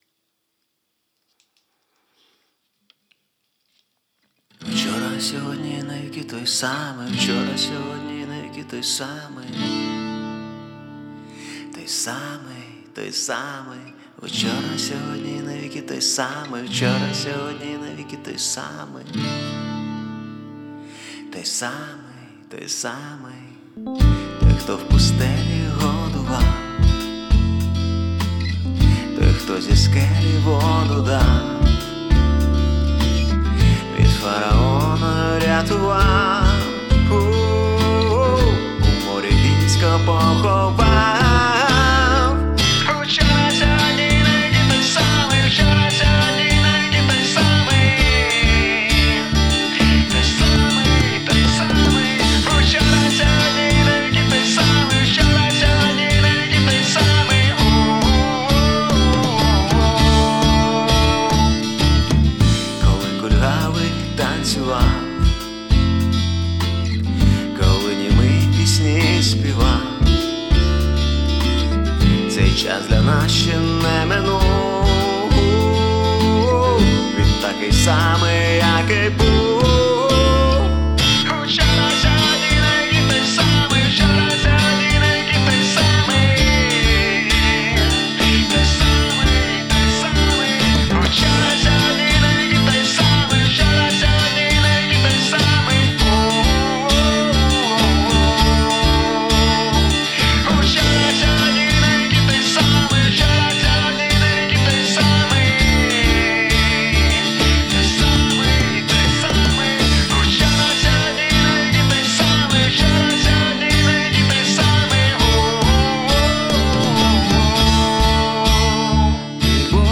92 просмотра 72 прослушивания 0 скачиваний BPM: 100